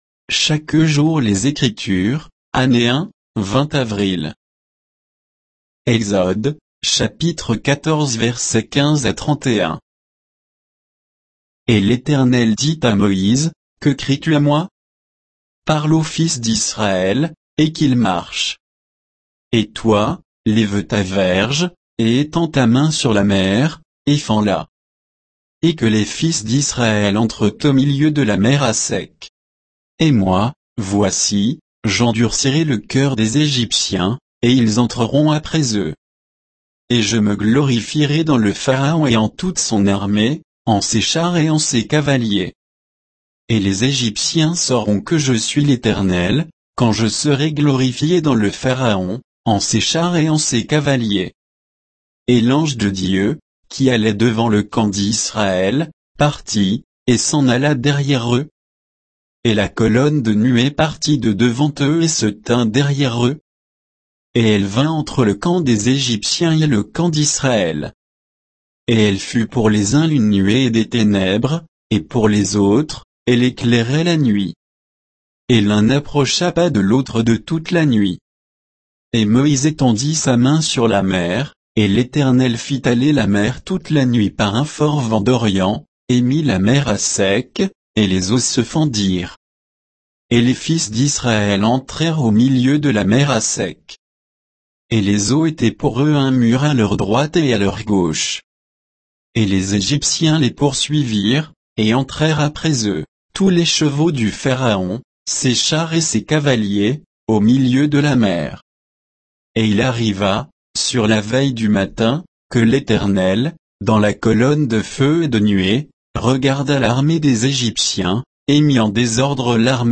Méditation quoditienne de Chaque jour les Écritures sur Exode 14, 15 à 31